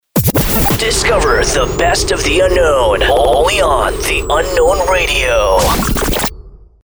UnknownRadioSWEEPER2